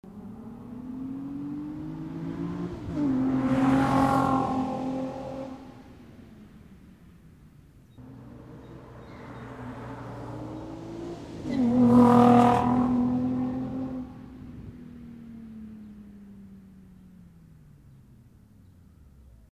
RSR_DriveBy_WITHsilencers.w